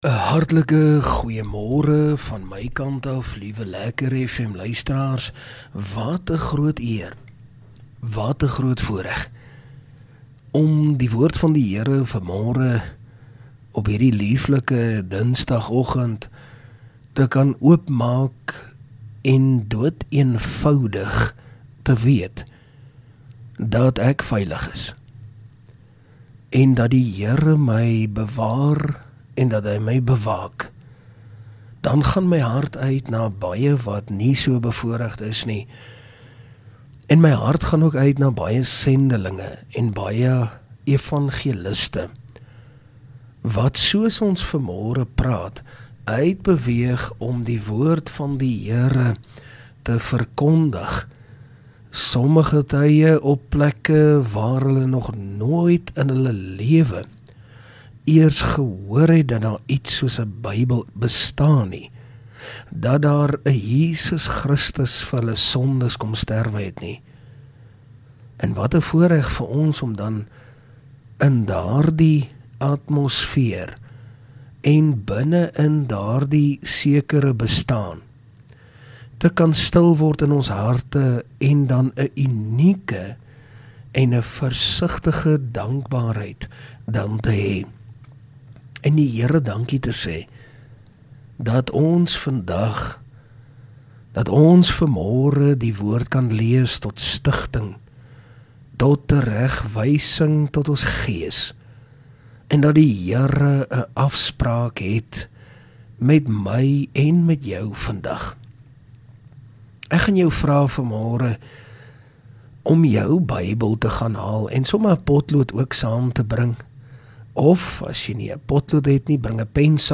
Oggendoordenking